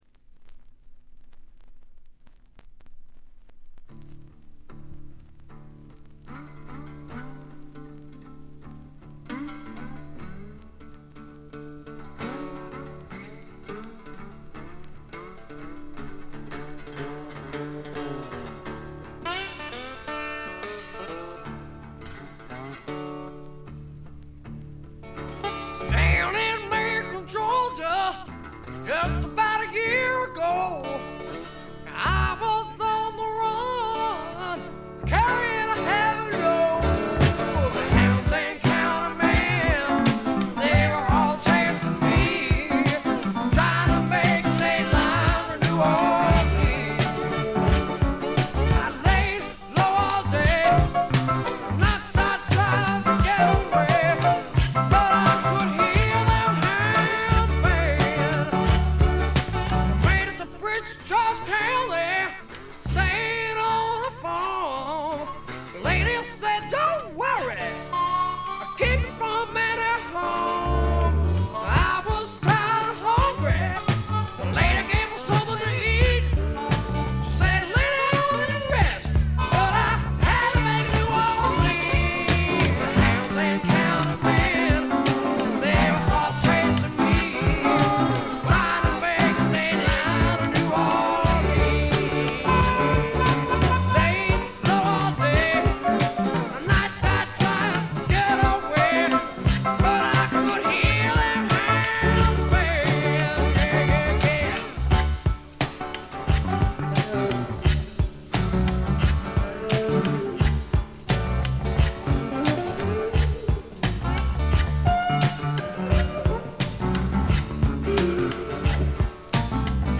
軽度のバックグラウンドノイズ、ところどころでチリプチ。
最小限の編成が織り成す、ねばつくような蒸し暑いサウンド。
試聴曲は現品からの取り込み音源です。